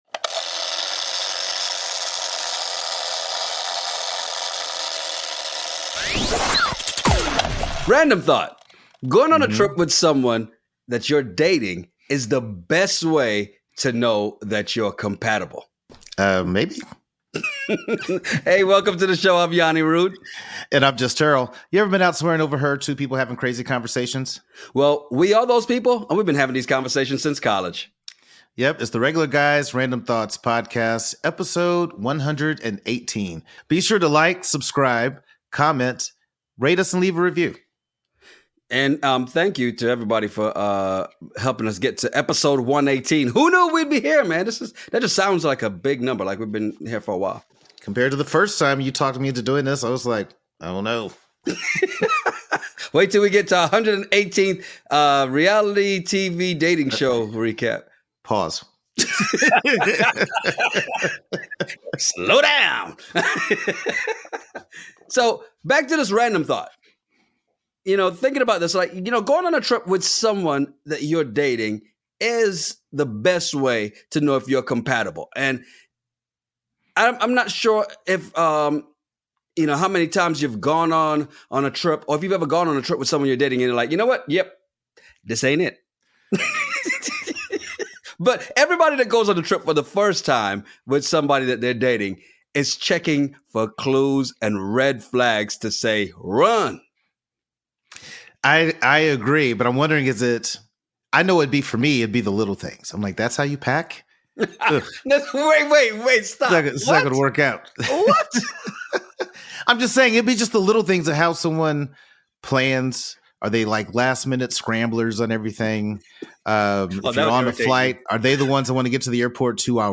Ever been somewhere and overheard two guys having a crazy conversation over random topics? Well we are those guys and we have been having these conversations since college.